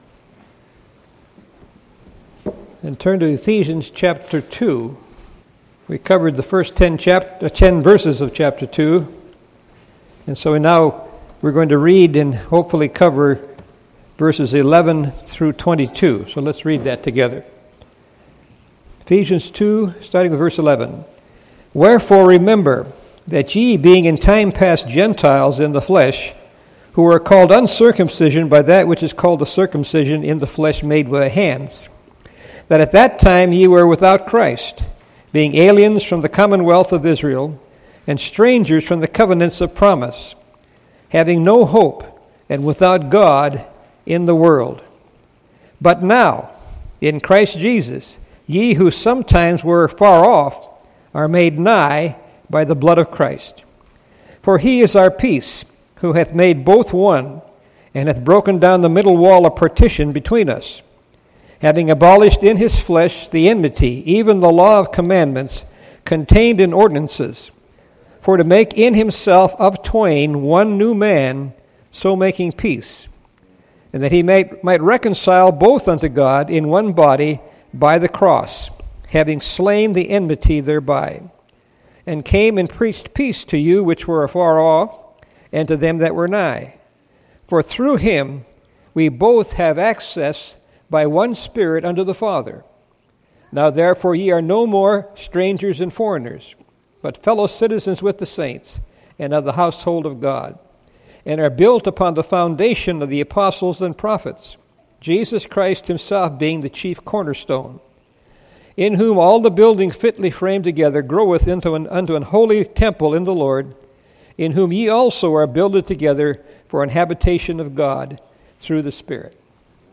Series: 2016 July Conference
Session: Morning Session